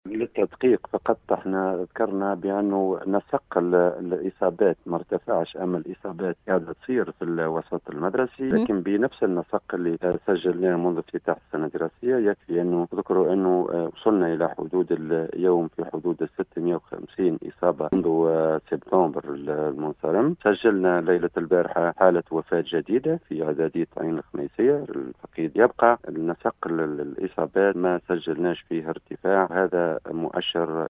أفاد المندوب الجهوي للتربية بالقصرين المنصف القاسمي في تصريح لاذاعة السيليوم أف أم اليوم الأحد 23 ماي 2021  أنّ الوضع الوبائي بالوسط المدرسي مستقر حيث لم يشهد ارتفاعا في نسق الإصابات ، و أنّ الجهود متواصلة منذ مفتتح السّنة الدّراسيّة للحفاظ على سلامة الإطار التربوي و التّلاميذ